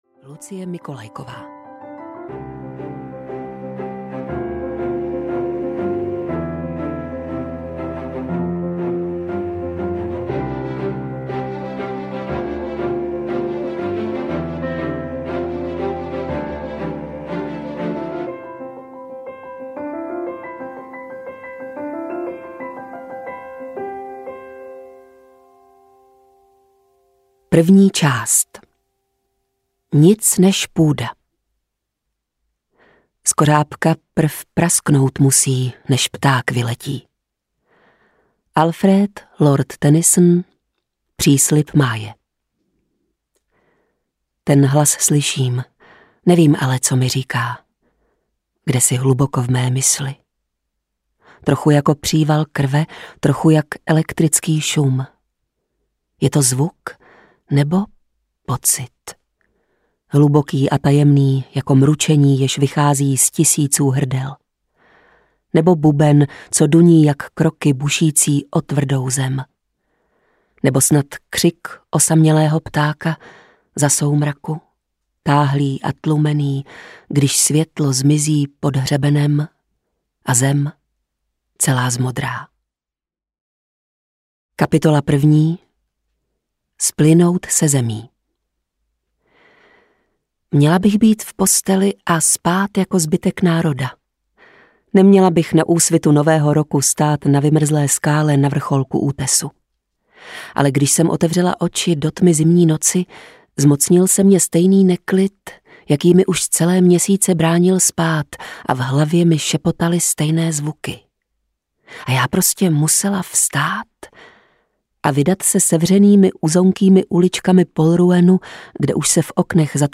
Bouřlivé ticho audiokniha
Ukázka z knihy
bourlive-ticho-audiokniha